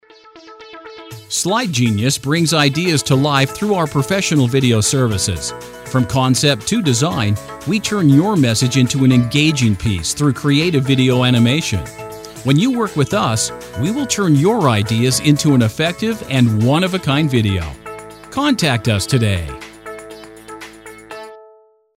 Voice over examples
Male Voice 3